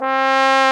Index of /90_sSampleCDs/Roland L-CDX-03 Disk 2/BRS_Bs.Trombones/BRS_Bs.Bone Solo